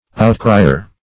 Outcrier \Out"cri`er\, n.